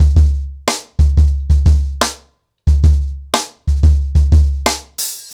CornerBoy-90BPM.15.wav